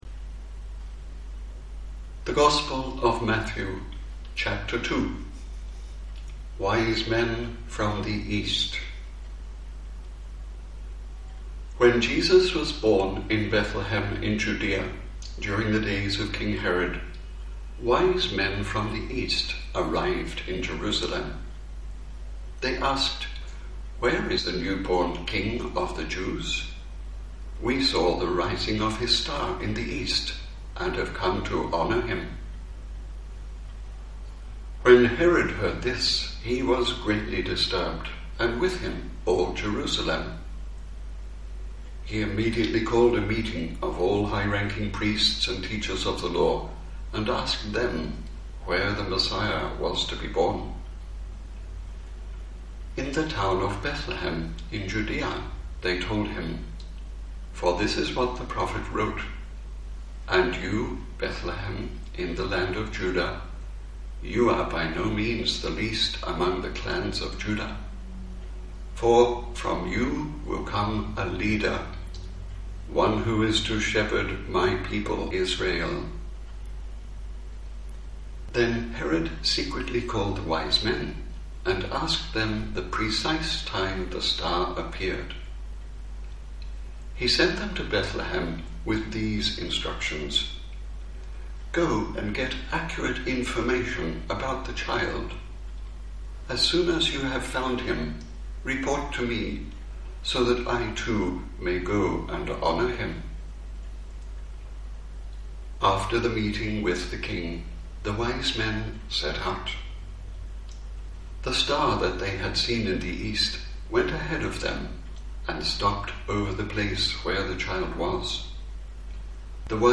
A meditate reading
slow enough to meditate and pray with the text